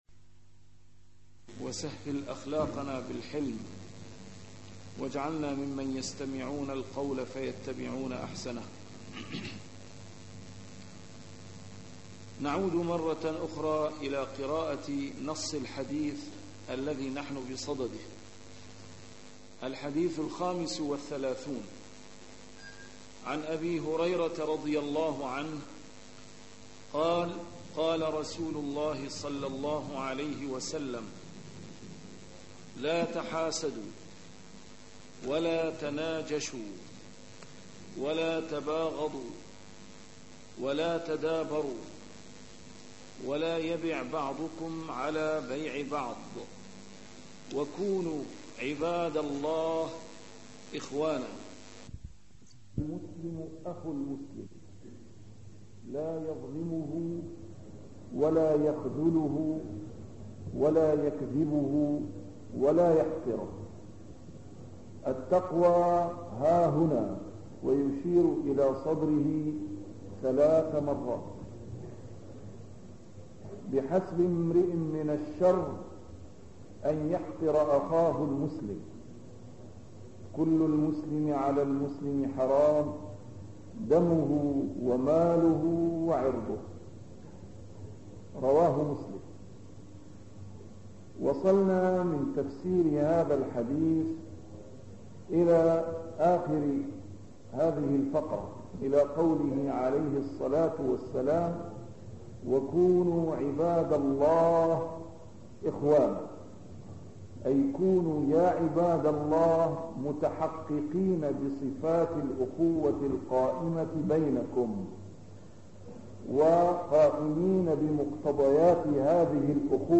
A MARTYR SCHOLAR: IMAM MUHAMMAD SAEED RAMADAN AL-BOUTI - الدروس العلمية - شرح الأحاديث الأربعين النووية - تتمة شرح الحديث الخامس والثلاثون: حديث أبي هريرة (لا تحاسدوا ولا تناجشوا …) 116